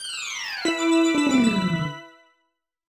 Minigame lost music